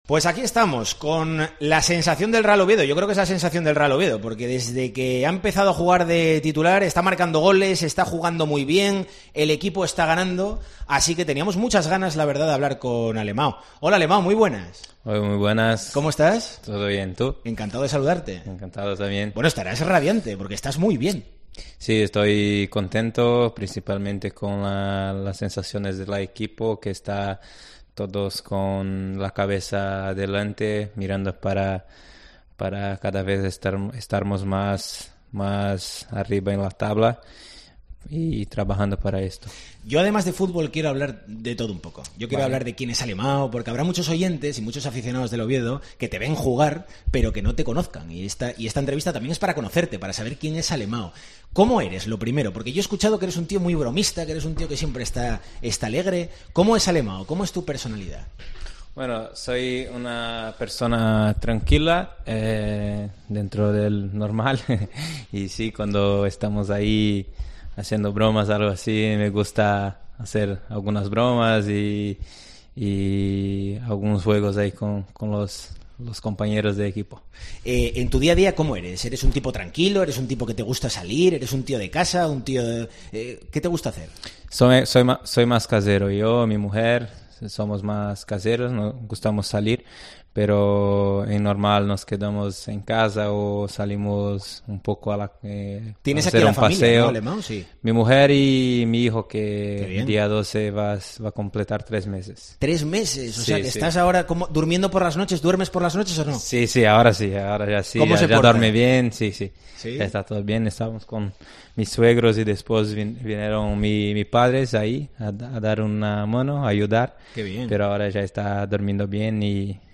El delantero brasileño, la gran sensación del Real Oviedo en este tramo final de temporada, ha concedido una entrevista en exclusiva a Deportes COPE...